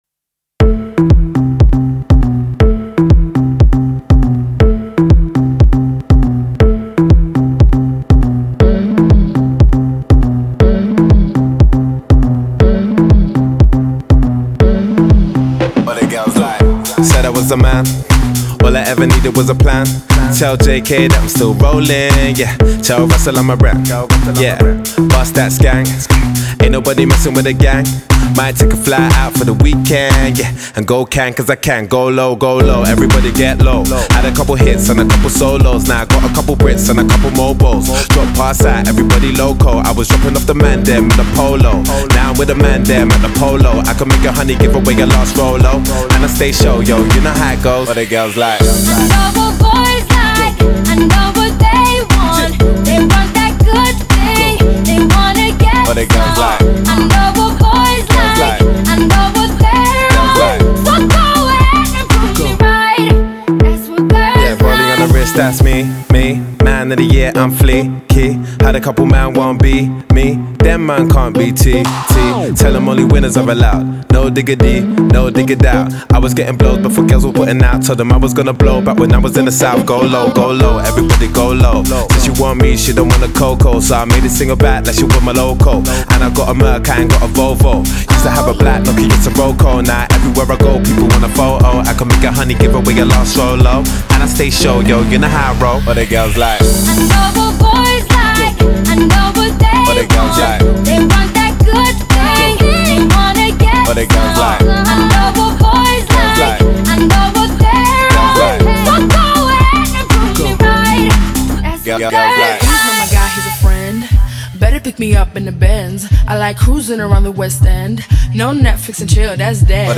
Категория: Поп Музыка